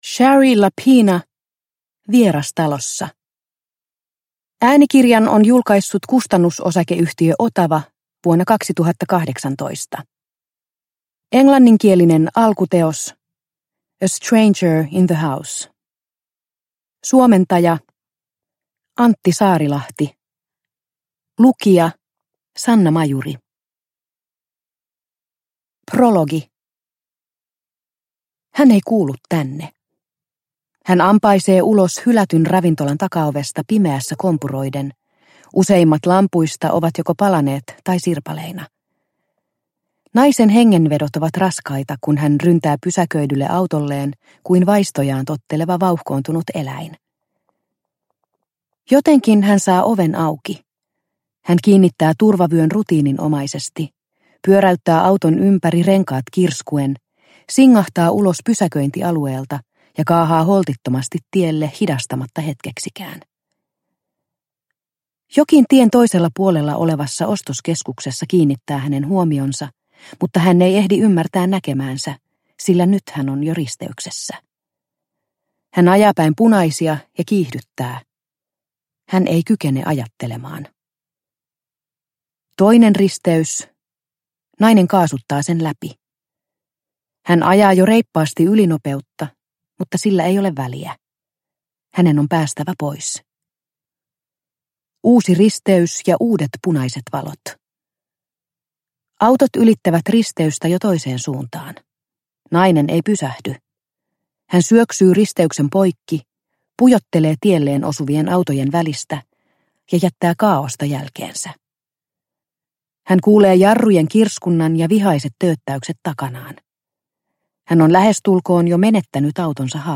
Vieras talossa – Ljudbok – Laddas ner